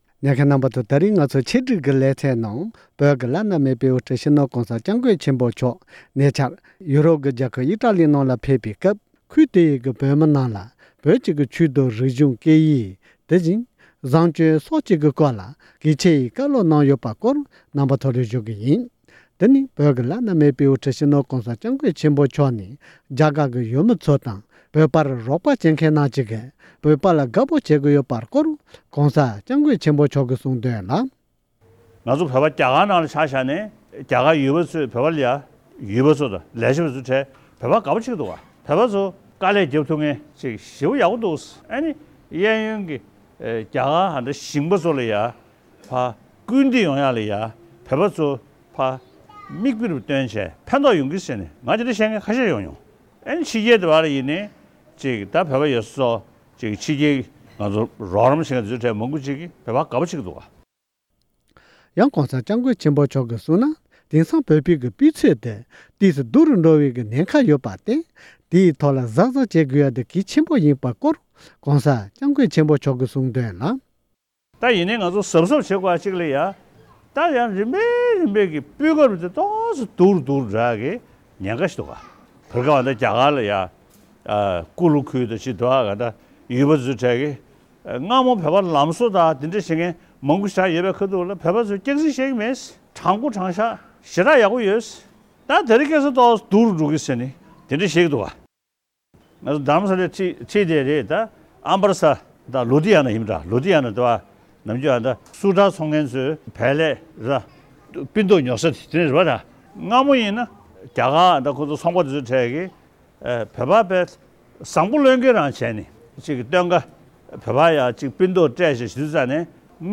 ཐེངས་འདིའི་ཁམས་སྐད་ཀྱི་ཆེད་སྒྲིག་ལེ་ཚན་ཟེར་བའི་ནང་།